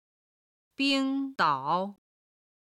冰岛　(Bīng dǎo)　アイスランド